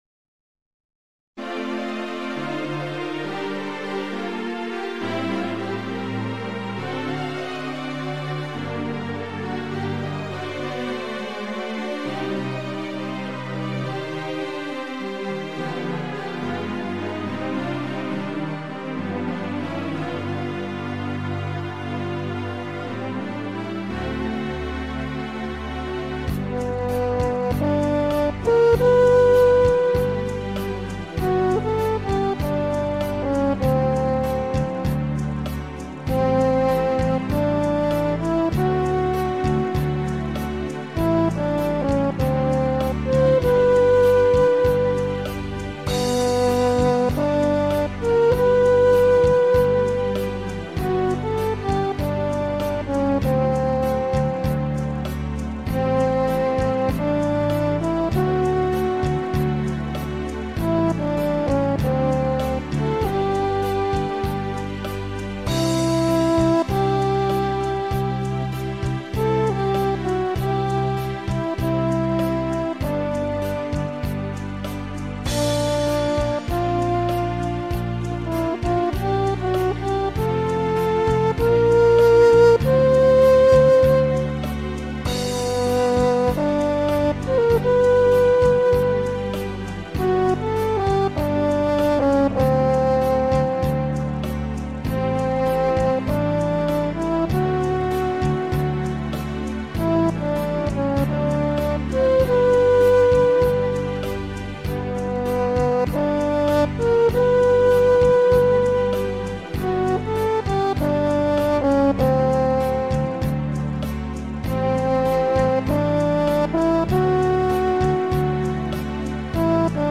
Akai Ewi 5000
91 French Horn2
• BackTrack au format Mp3, importé en audio dans Cubase